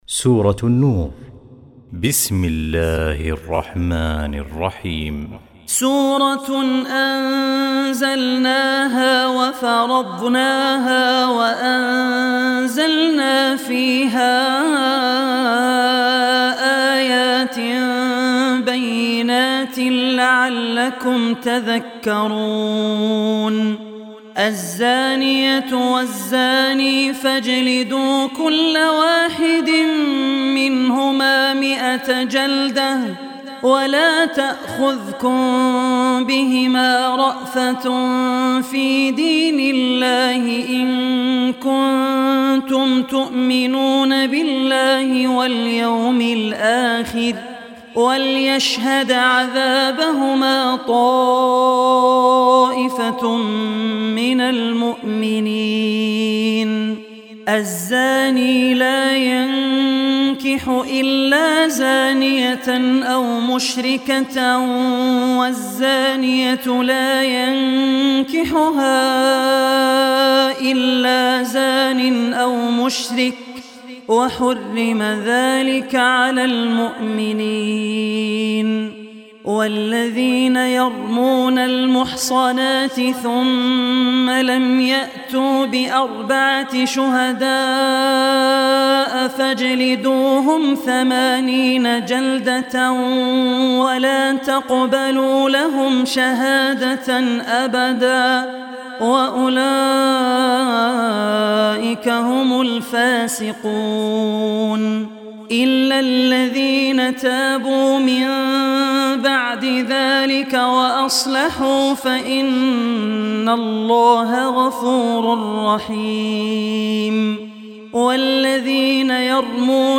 Surah Nur Recitation by Abdul Rehman Al Ossi
Surah Nur, listen online mp3 tilawat / recitation in the voice of Sheikh Abdul Rehman Al Ossi.